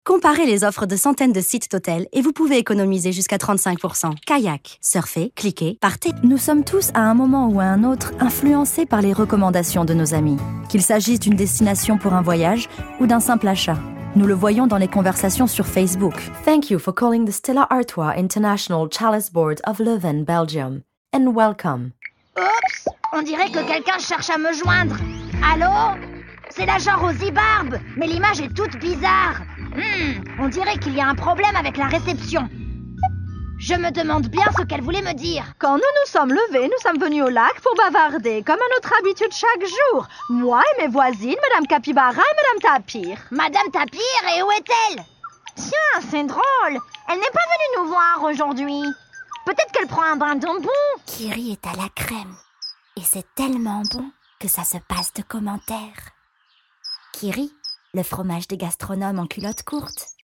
Demonstração Comercial
DiversãoDinâmicoAmigáveis